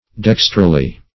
Search Result for " dextrally" : The Collaborative International Dictionary of English v.0.48: Dextrally \Dex"tral*ly\, adv. Towards the right; as, the hands of a watch rotate dextrally.